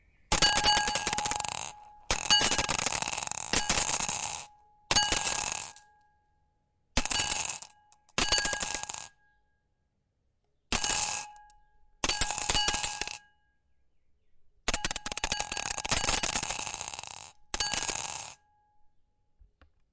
教堂钟声 " 大理石教堂
描述：市中心的教堂钟声，接着是远处的教堂钟声。 ( AKG C 535, Marantz PMD660 )
标签： marmorkirke 遥远 交通 城市 现场录音 大理石教堂 教堂
声道立体声